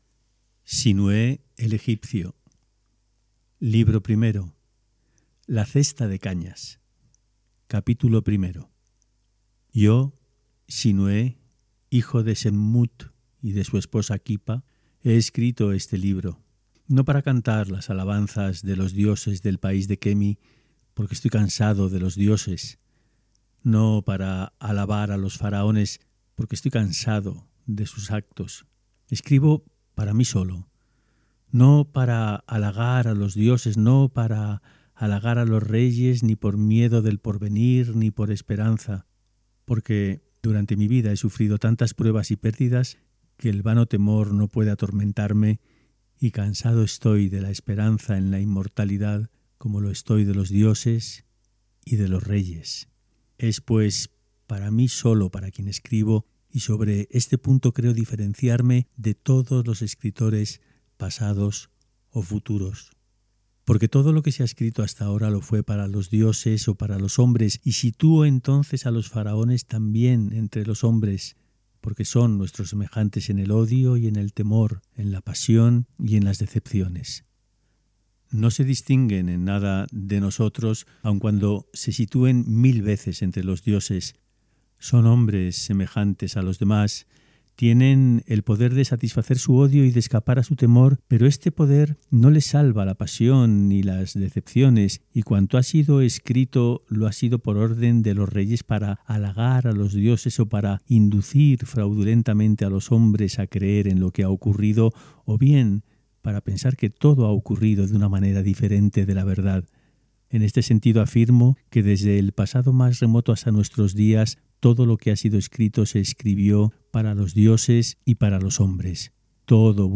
Lecturas en alta voz